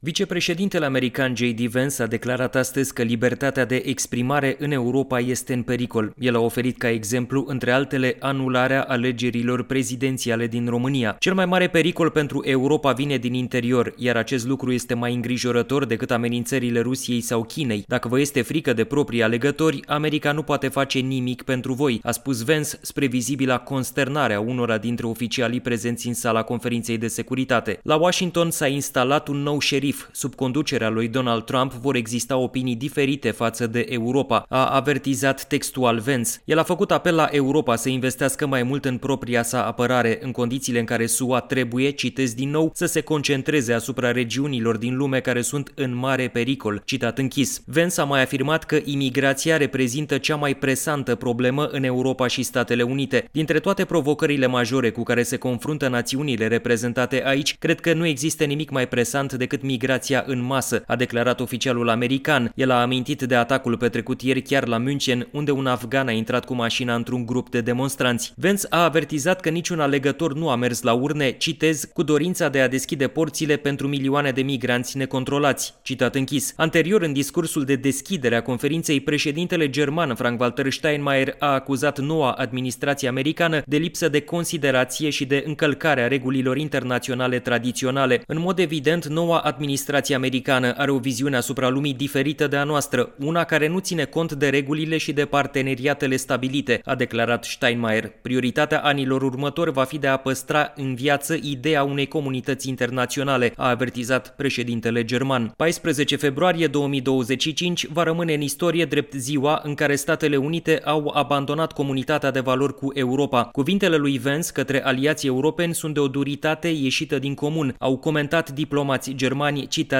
transmite din Germania: